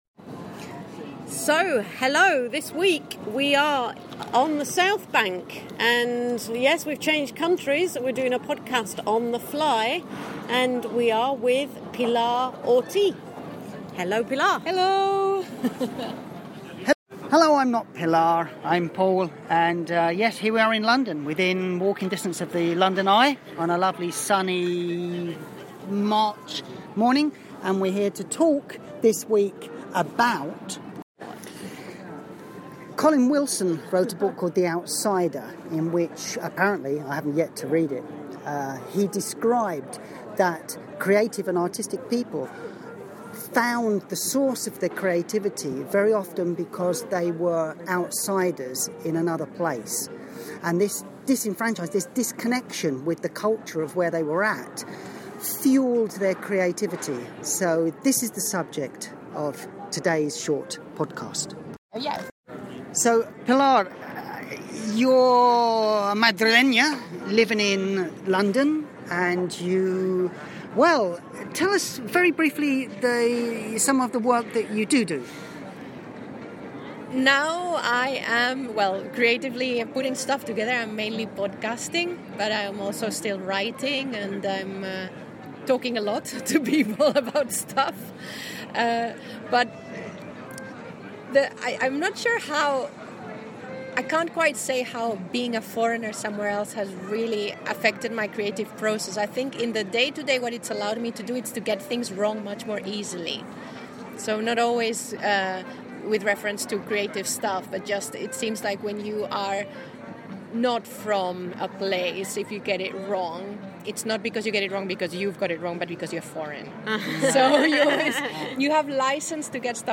discuss these issues as they sit in the march sunshine on the south bank in London